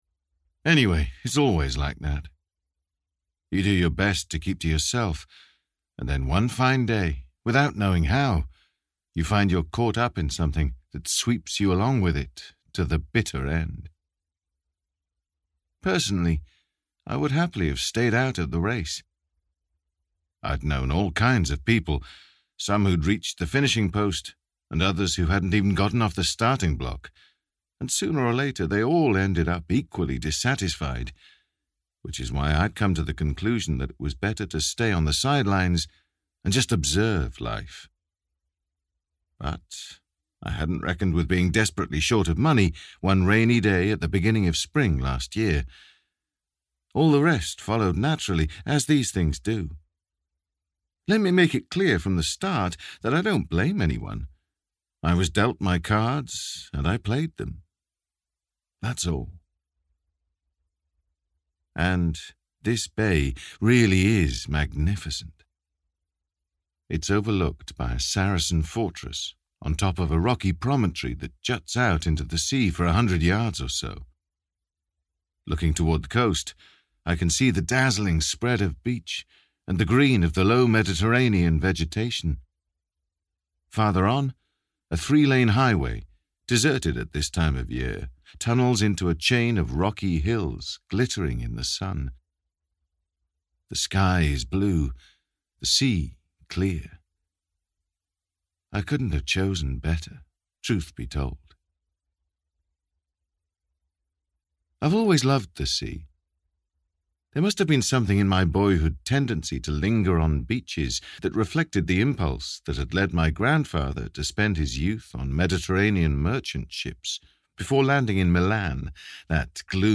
Last Summer in the City - Vibrance Press Audiobooks - Vibrance Press Audiobooks